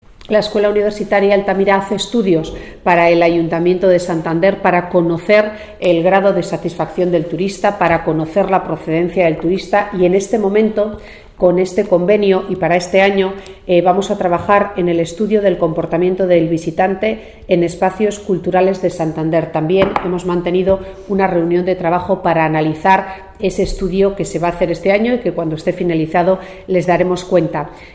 Audio de Gema Igual: